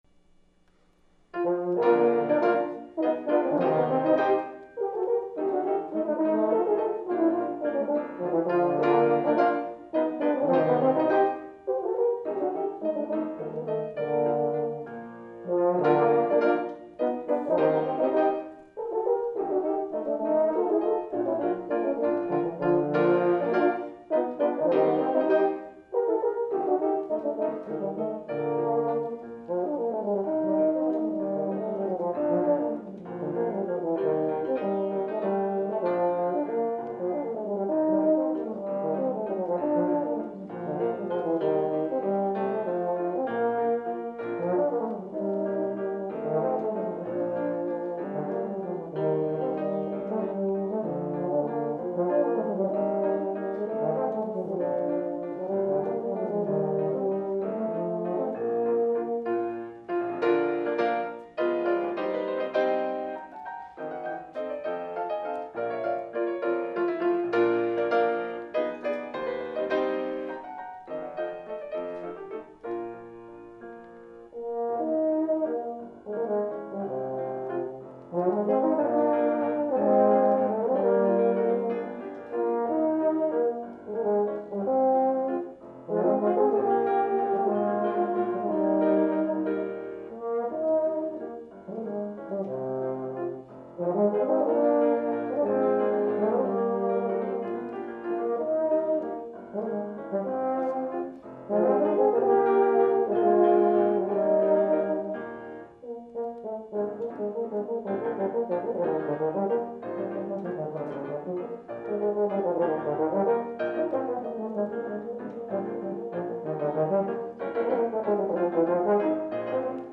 Voicing: Euphonium Duet